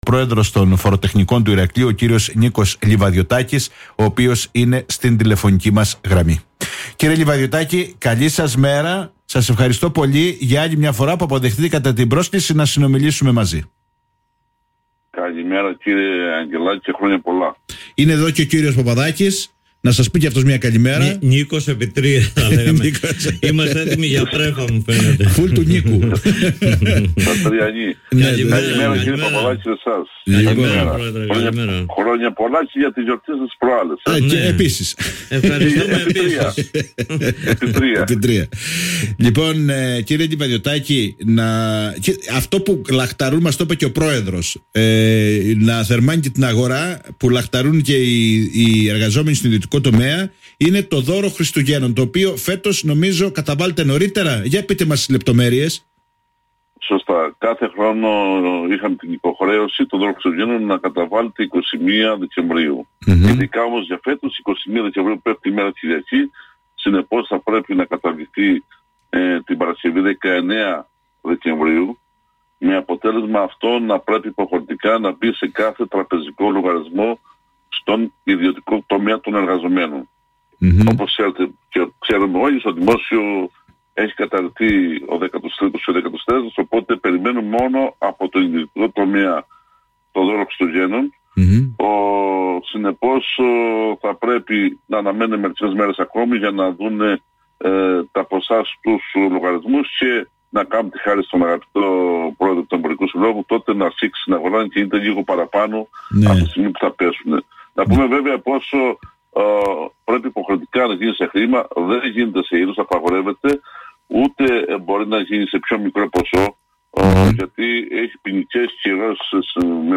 μιλάει στην εκπομπή “Όμορφη Μέρα”